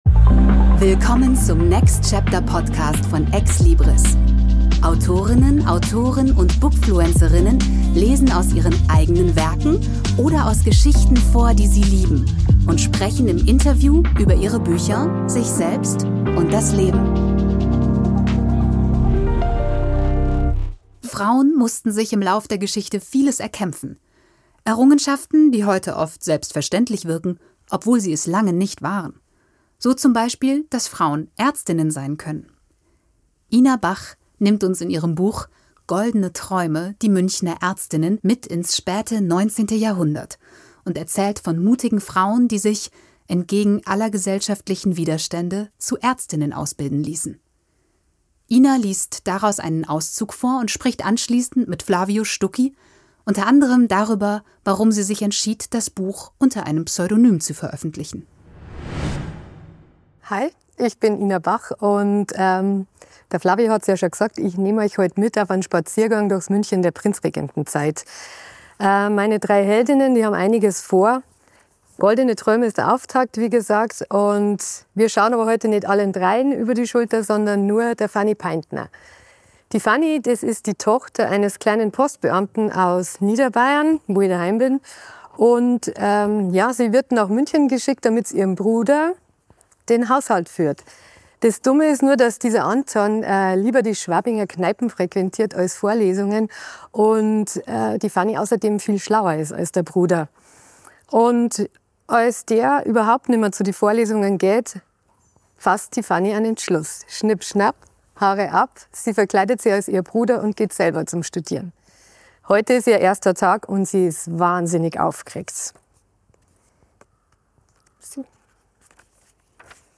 liest daraus einen Auszug vor